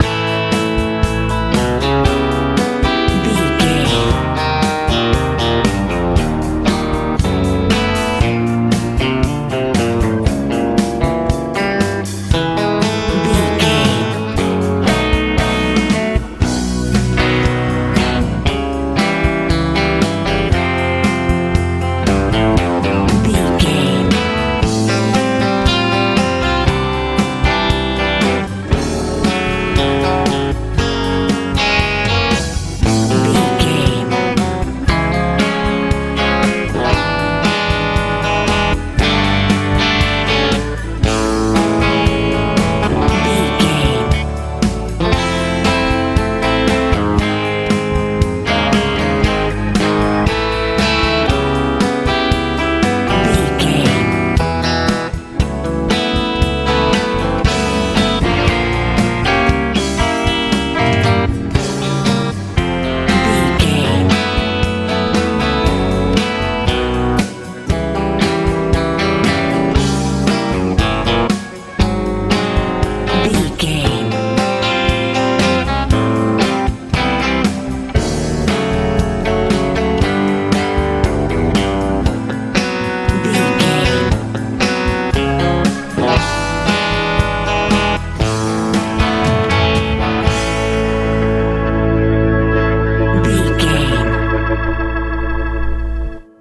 lite pop feel
Ionian/Major
hopeful
joyful
organ
acoustic guitar
bass guitar
drums
80s
90s